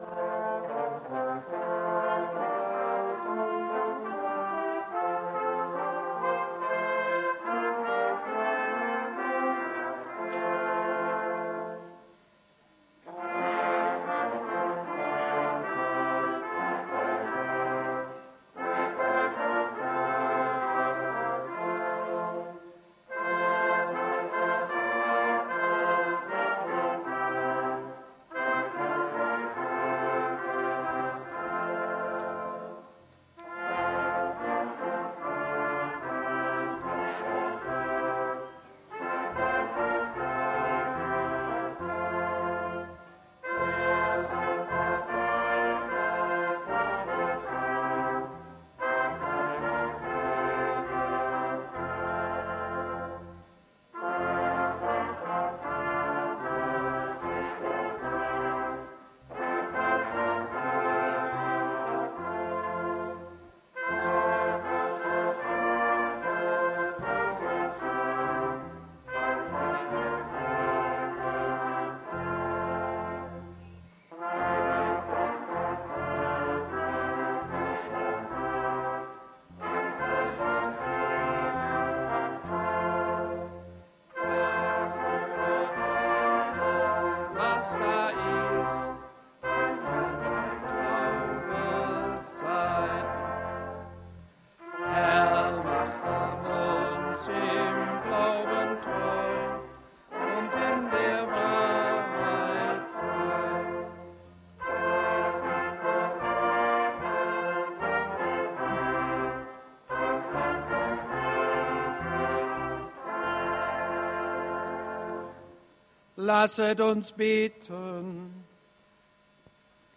Seit einigen Jahren schon zur guten Tradition geworden, der gemeinsamer ökumenischer Stadtgottesdienst im Rahmen des Rolandsfestes. Unter strahlend blauen Himmel fanden sich Menschen unterschiedlicher Kirchen unserer Stadt vor dem Theater zusammen um am 1.Sonntag nach Trinitatis Gottesdienst zu feiern.
Es wurde zur Ehre Gottes, viel gesungen, tolle Musik erklang von der Bühne, an dieser Stelle großen Dank an alle beteiligten Musiker, so könnte Ökumene in Nordhausen aussehen.